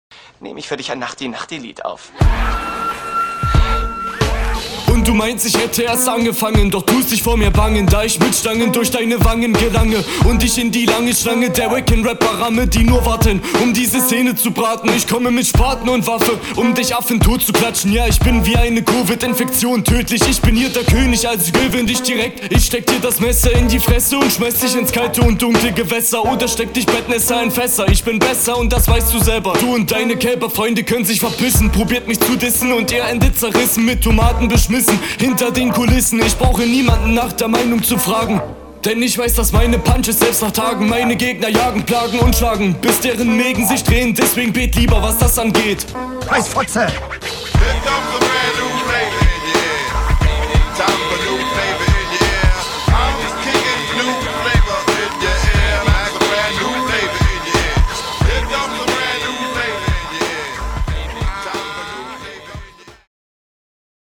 Klingst zu angestrengt und so, als ob du schreist, was null zum Beat passt.